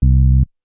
SUB I.wav